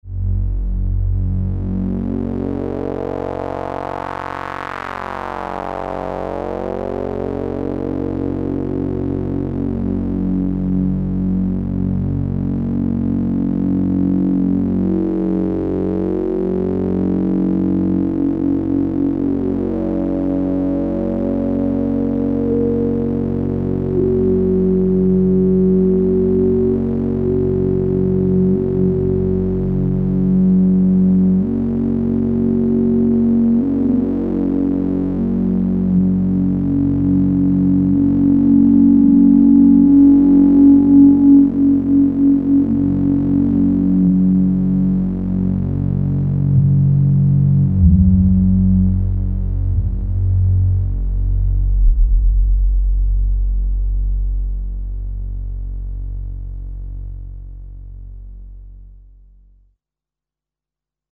The sound could be described as very vintage, full bodied, and animated.
Resonant drone: Two oscillators through one band-pass filter each in stereo. Filter cutoff and resonance of both filters tweaked.
Unease_P9700s_resonant_drone.mp3